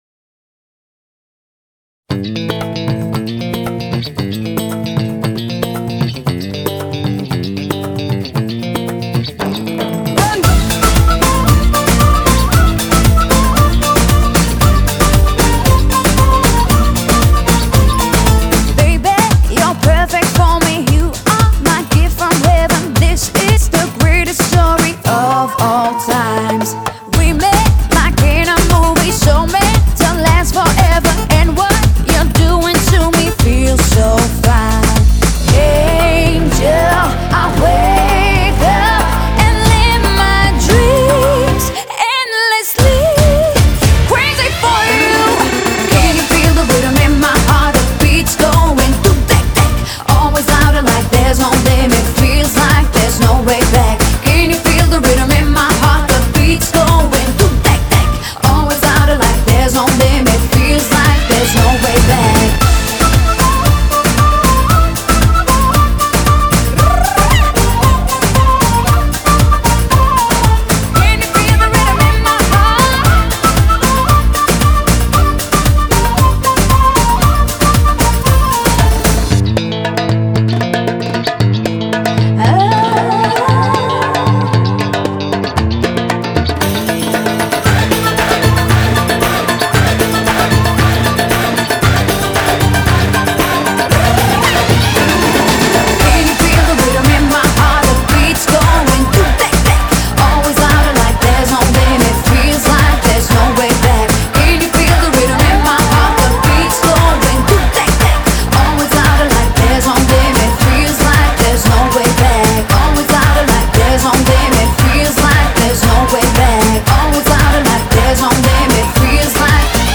BPM115-460
Audio QualityPerfect (High Quality)
CommentsEurovision 2009 represent